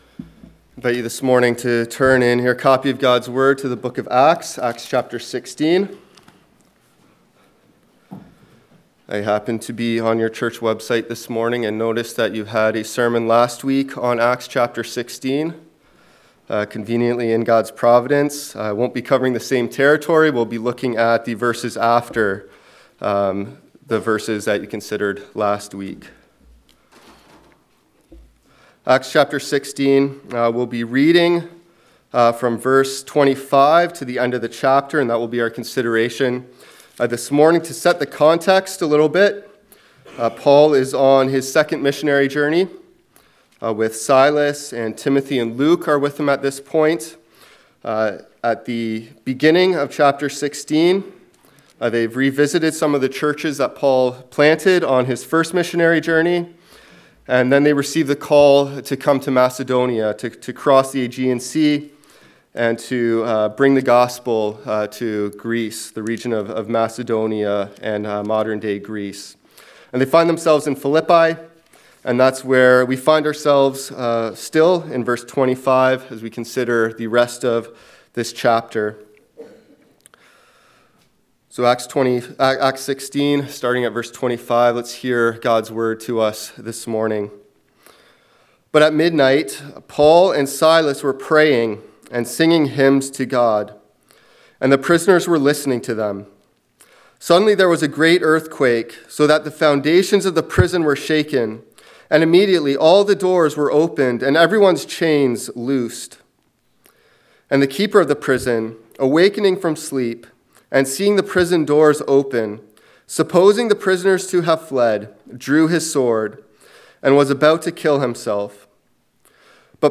Passage: Acts 16 :25-40 Service Type: Sunday Morning